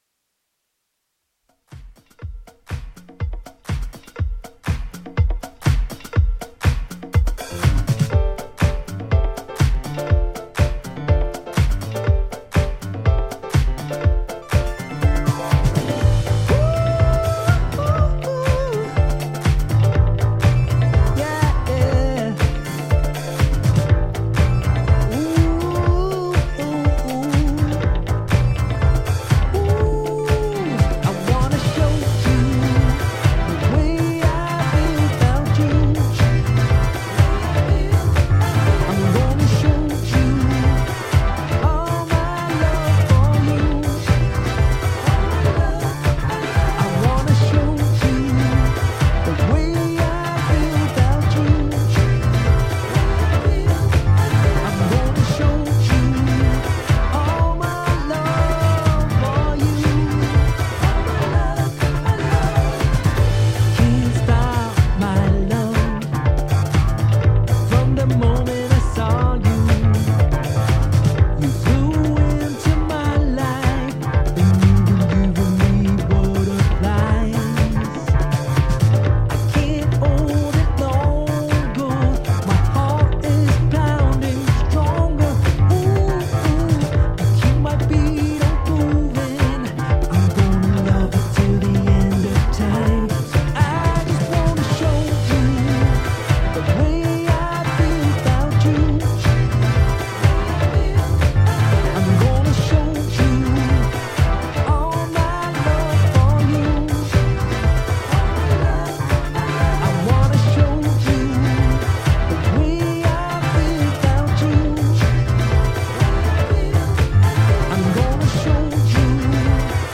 ジャンル(スタイル) NU DISCO / DEEP HOUSE / BOOGIE